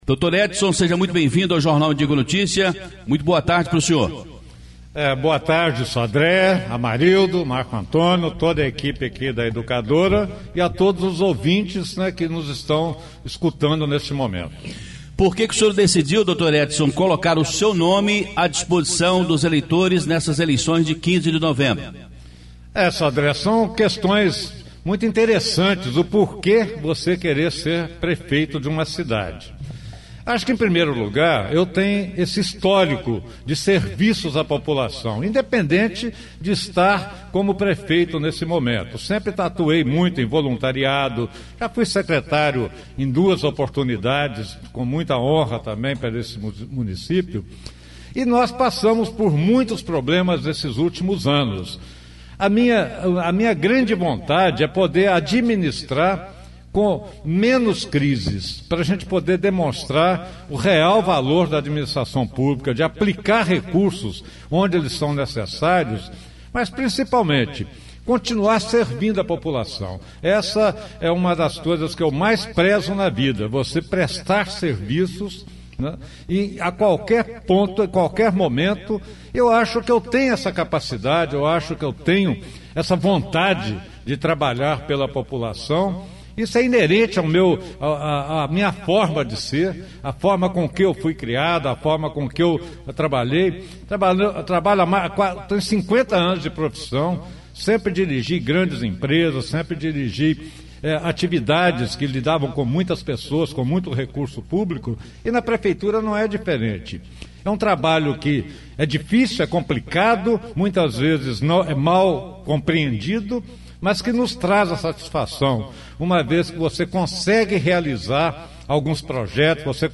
Educadora na boca da urna!Entrevista às 12h30.
O candidato terá 30 minutos para responder perguntas dos jornalistas
Entrevista exibida na Rádio Educadora AM/FM Ubá-MG